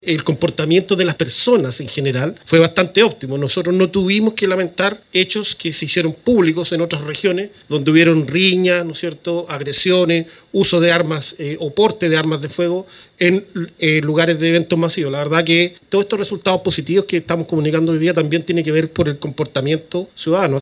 El jefe de la IX Zona de Carabineros en La Araucanía, general Manuel Cifuentes, destacó el comportamiento de la ciudadanía.